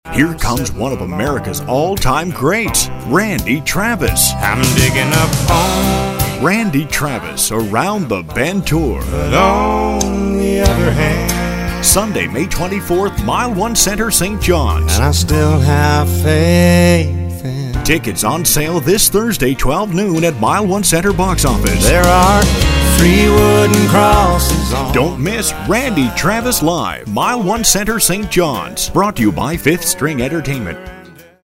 30 second concert promo